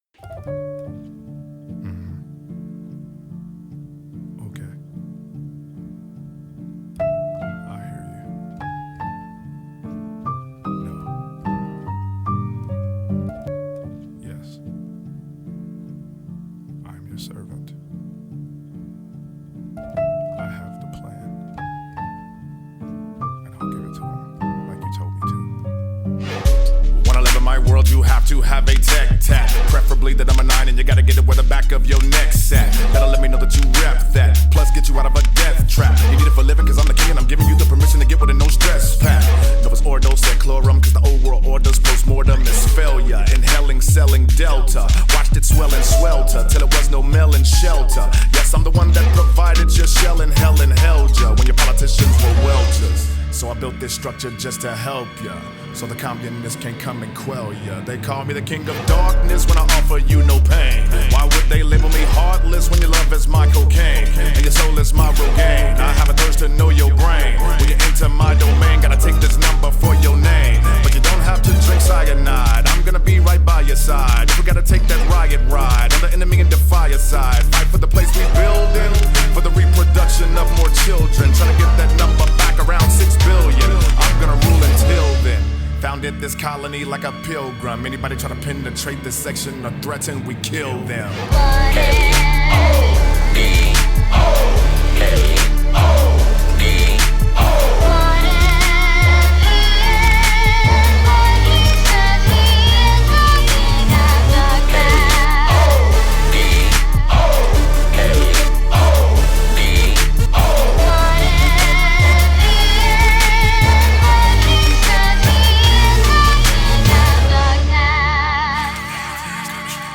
BPM74-148
Audio QualityPerfect (High Quality)